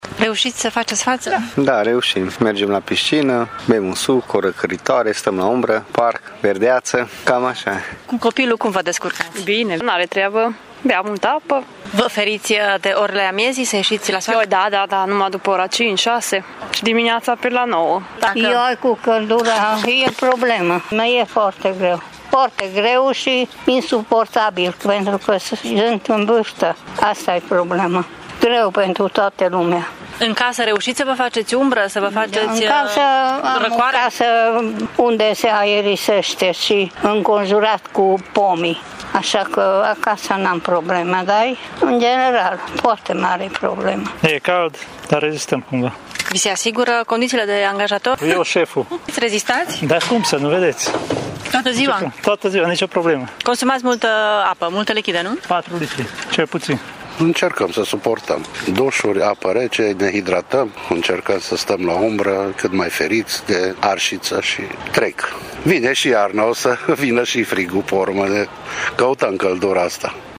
Târgumureșenii reușesc să facă față temperaturilor ridicate, însă cel mai greu este pentru vârstnici și cei cu boli cronice: